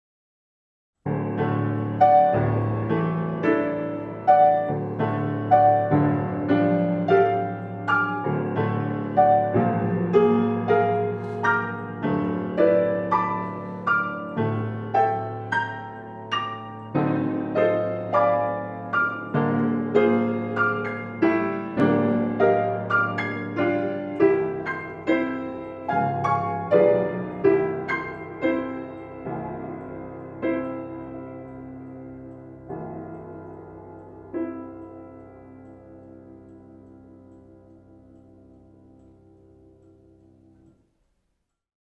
Musik zum Mantra 17 Q — martialisch